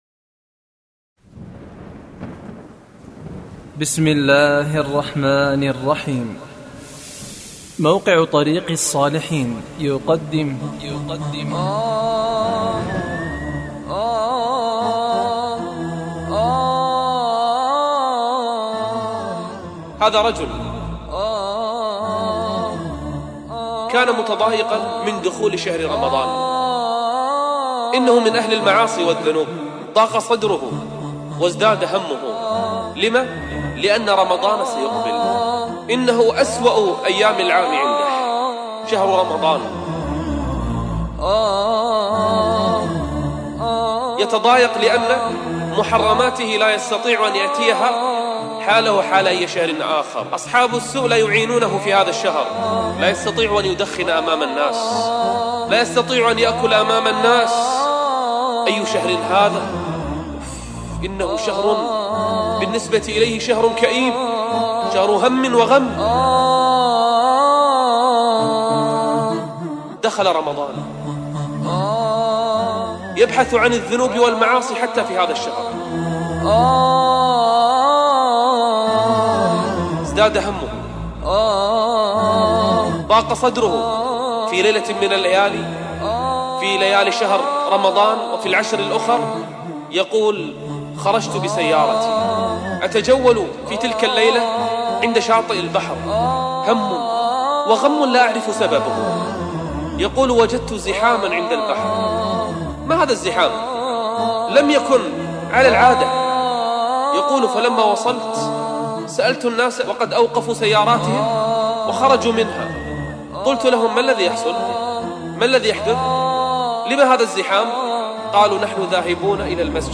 المهم كنت ابغى منكن تجدوا او تستخرجوا الكلمات من محاضرة شيخ نبيل العوضي